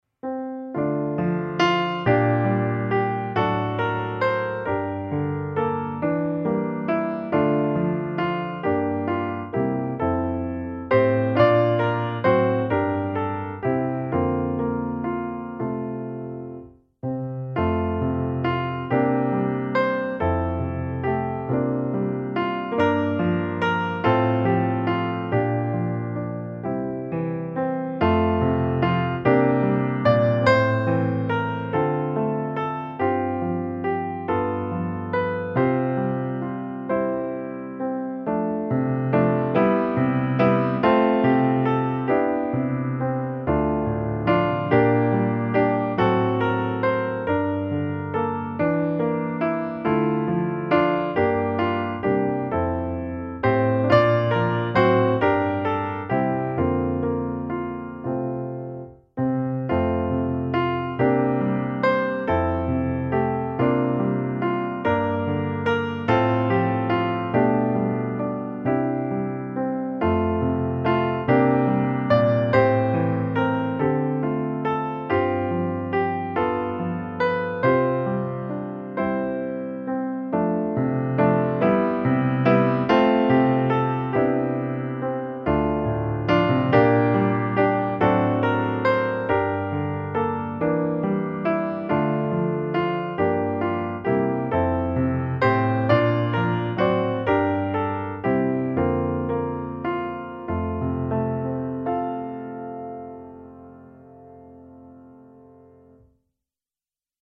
De blomster som i marken bor - musikbakgrund